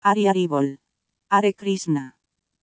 Spanish Chanting.mp3